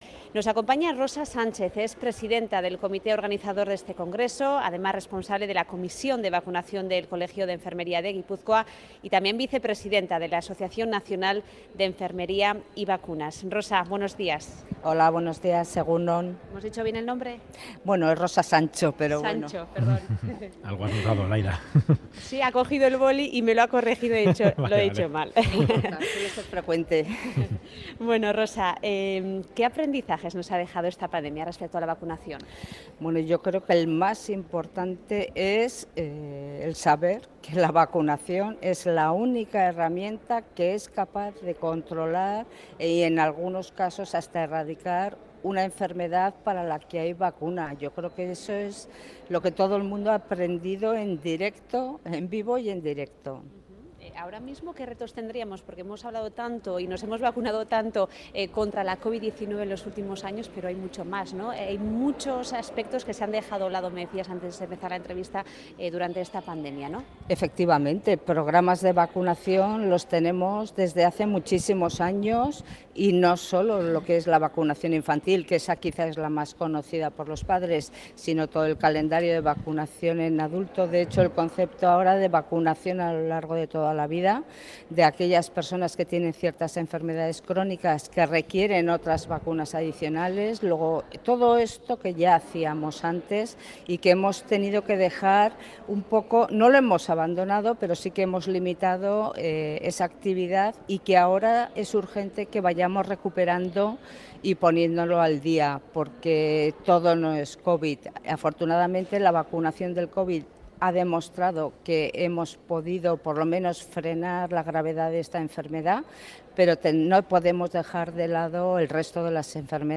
Radio Euskadi ENTREVISTA